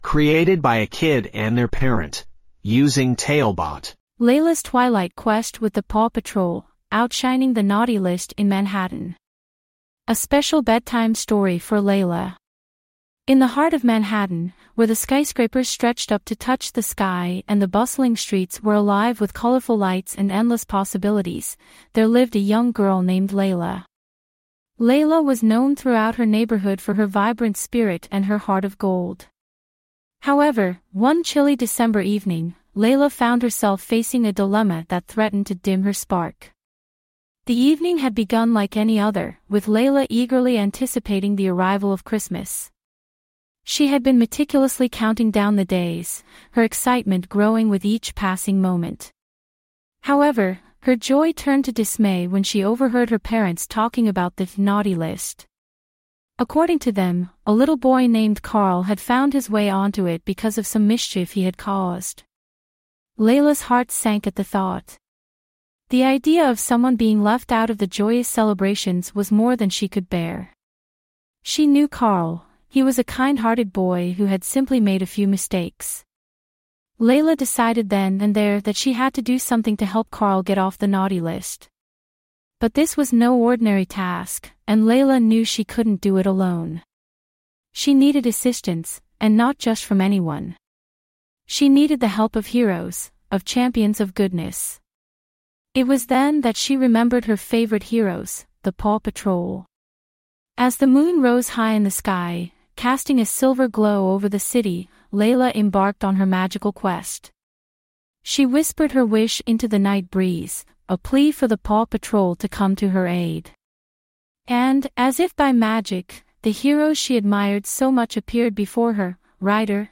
5 minute bedtime stories.
Write some basic info about the story, and get it written and narrated in under 5 minutes!